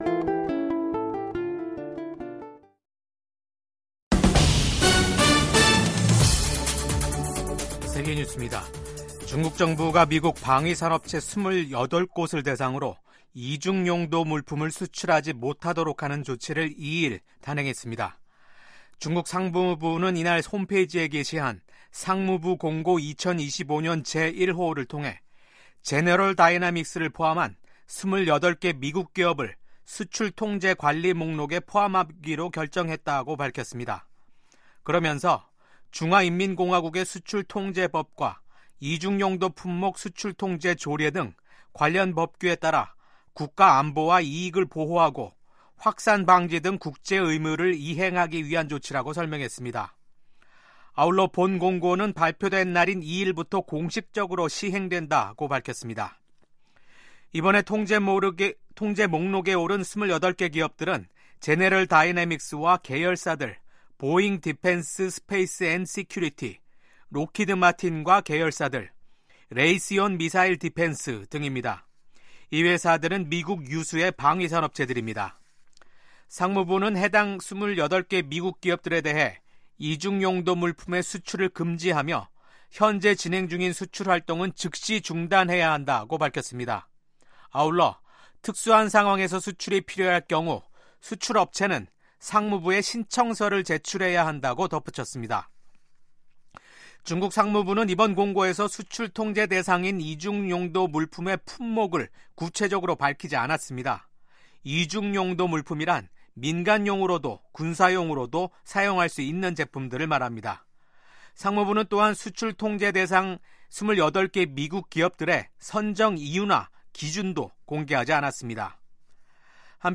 VOA 한국어 아침 뉴스 프로그램 '워싱턴 뉴스 광장'입니다. 주한 미국 대사와 전현직 주한미군 사령관들이 신년사에서 미한동맹의 굳건함을 강조하며 동아시아 안보 강화를 위한 협력을 다짐했습니다. 전 세계에서 중국과 치열한 경쟁을 벌이고 있는 미국은 ‘미국 우선주의’를 내세운 도널드 트럼프 2기 행정부가 출범하면 한국에 중국 견제를 위한 더 많은 역할을 주문할 것으로 예상됩니다.